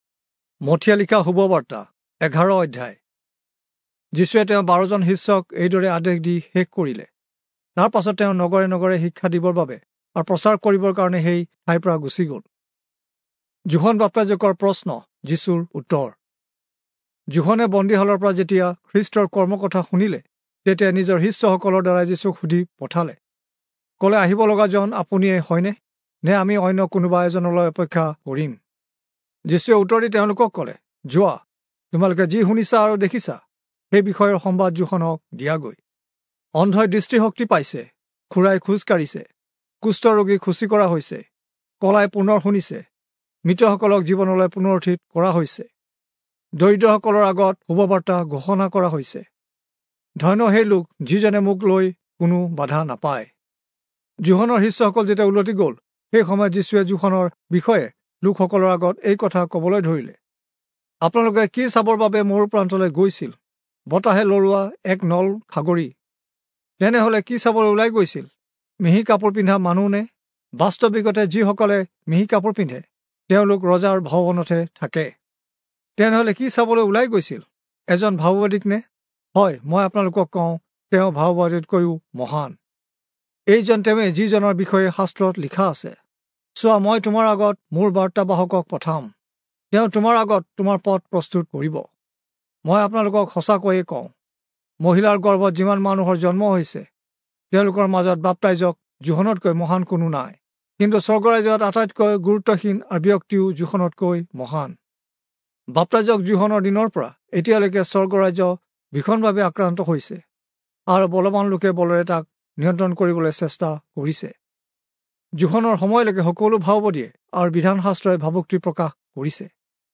Assamese Audio Bible - Matthew 22 in Hov bible version